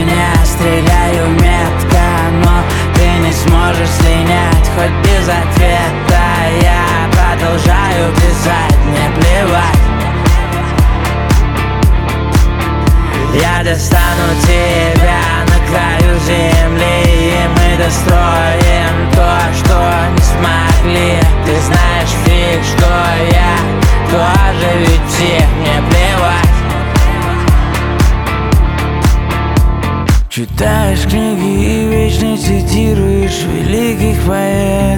Жанр: Поп музыка / Рок / Русский поп / Русские